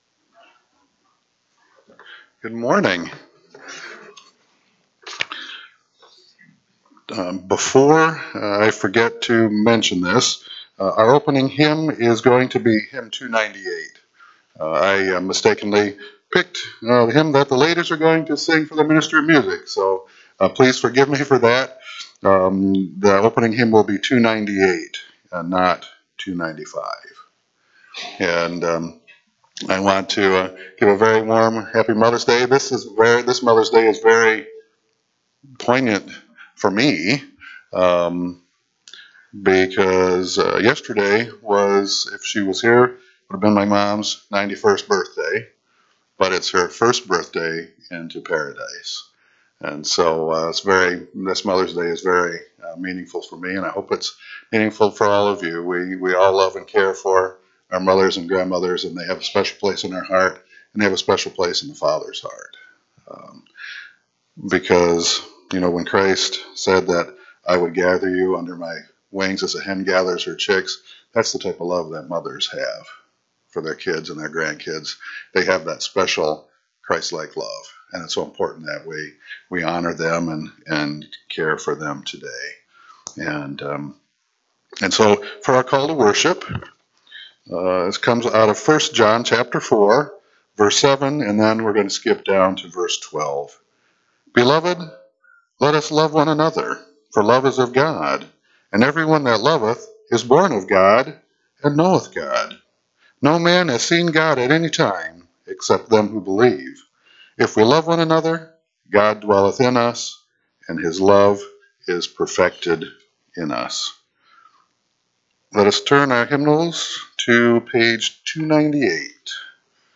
Lexington Restoration Branch - May 11, 2025 Service - Playeur
Women's Choir).mp3